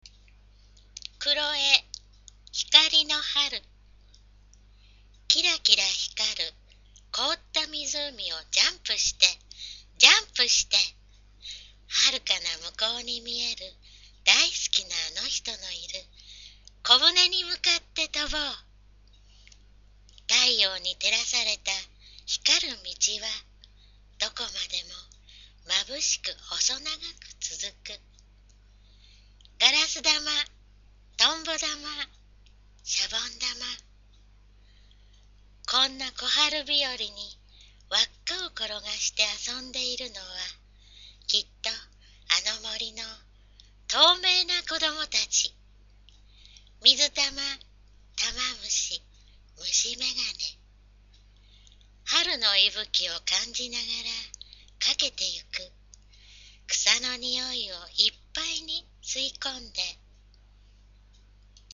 poemreadhikarinoharu001.mp3